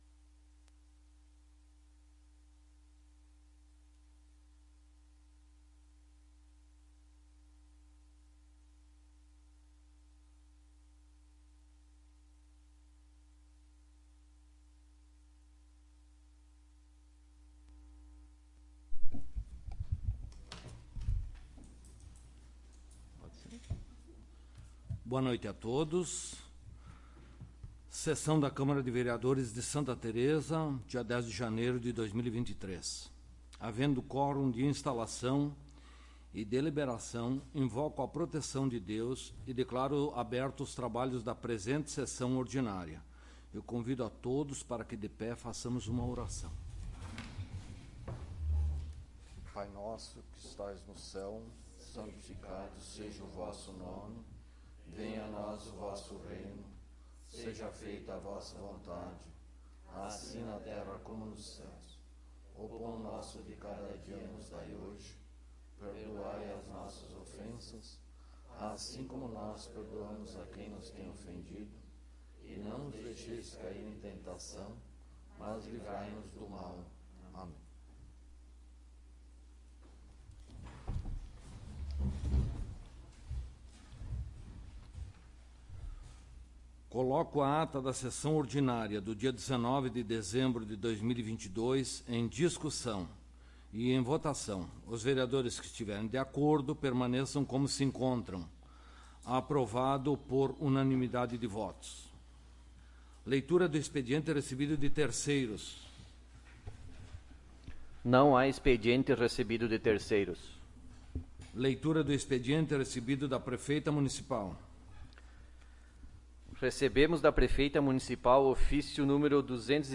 01° Sessão Ordinária de 2023
Áudio da Sessão